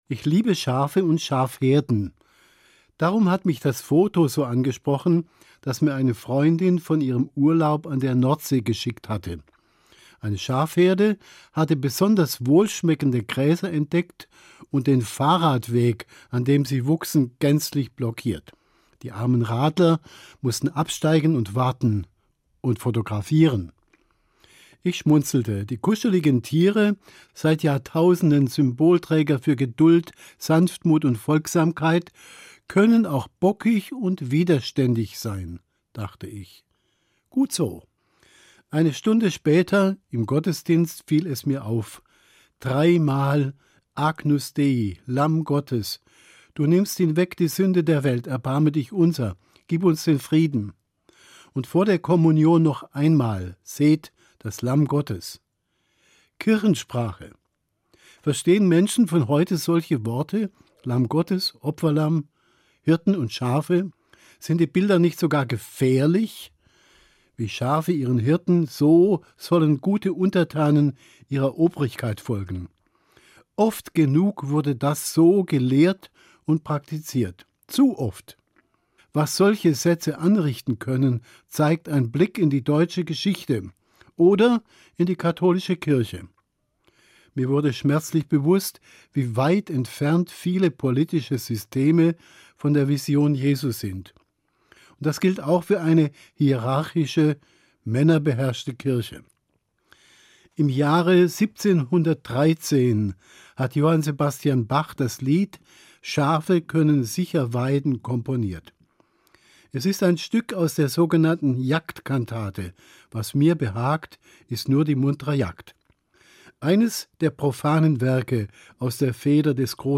Eine Sendung